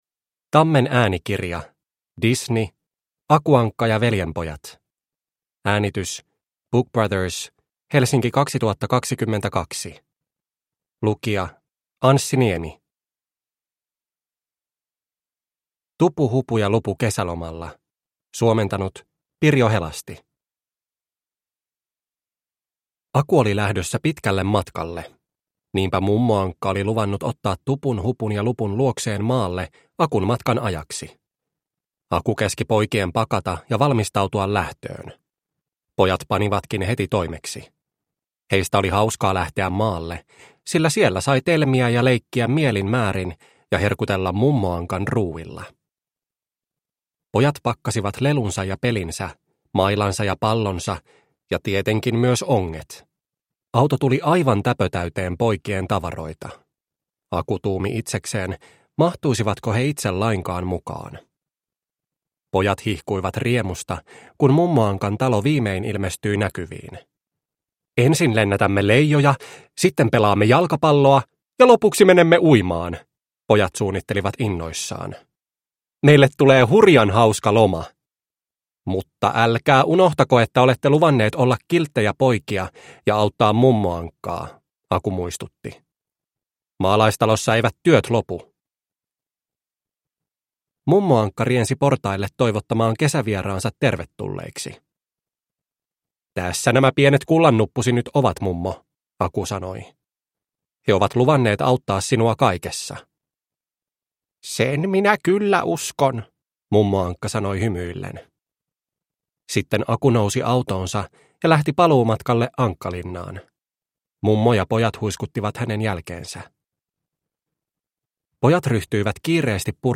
Aku Ankka ja veljenpojat – Ljudbok – Laddas ner